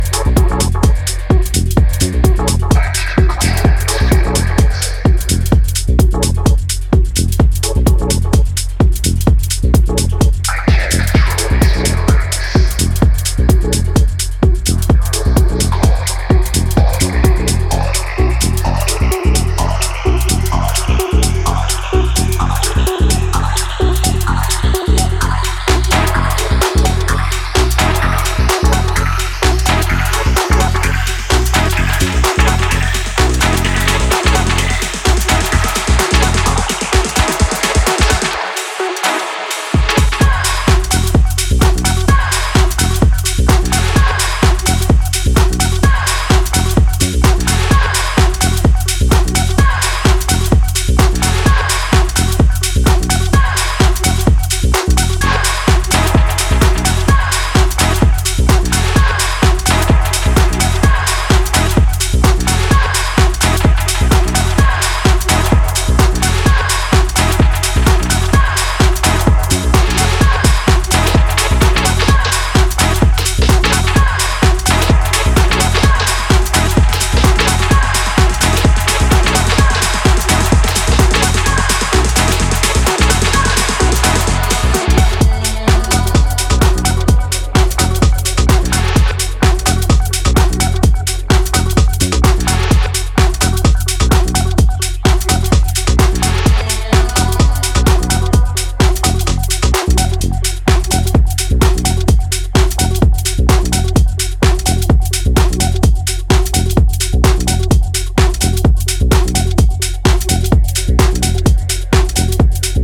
five tracks across house, electro and breaks
bold stabs, sampling grit, room-holding weight.